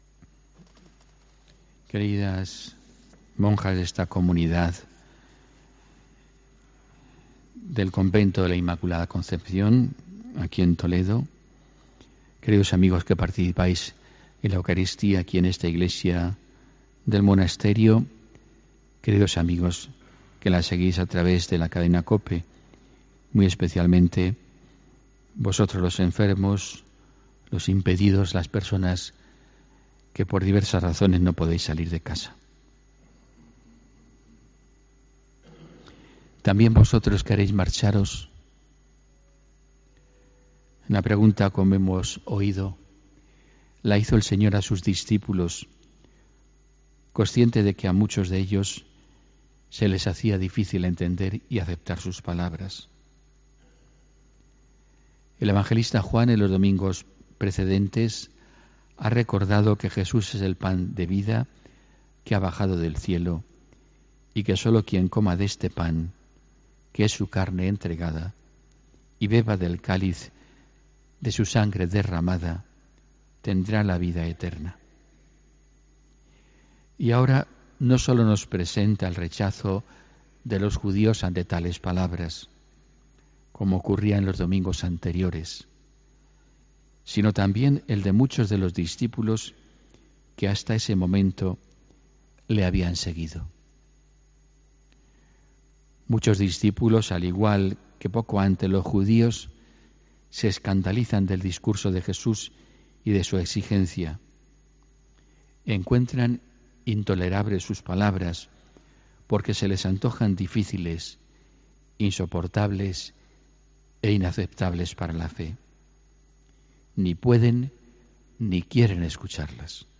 HOMILÍA 26 AGOSTO 2018